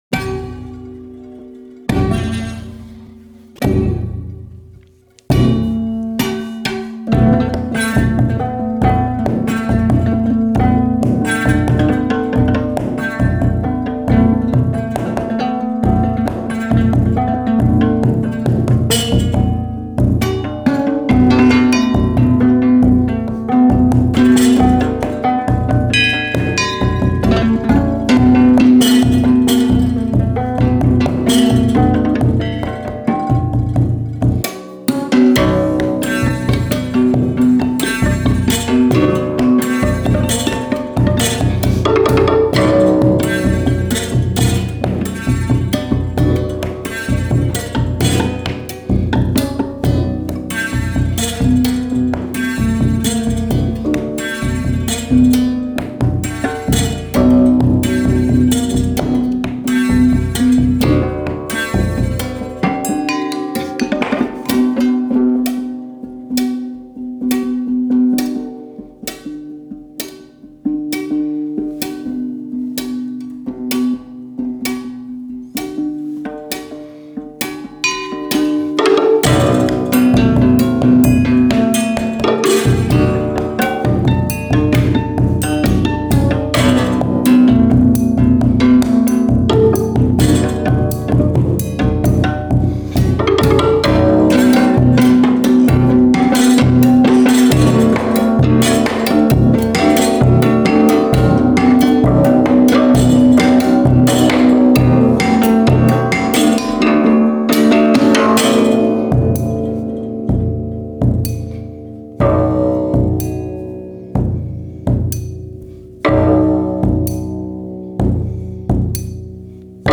Duo de musicien